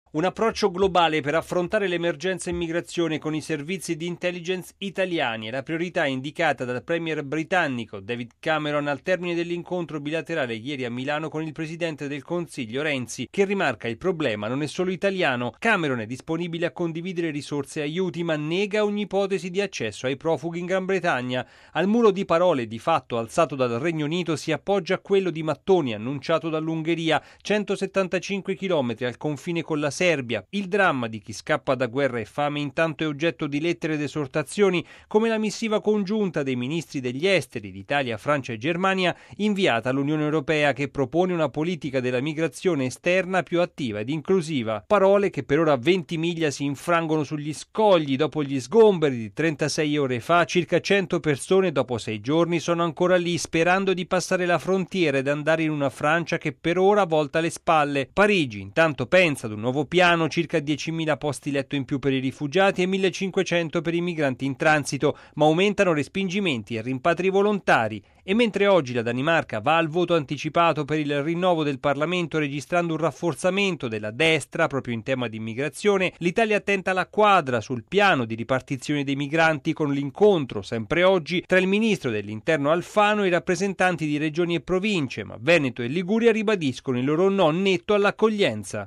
Il servizio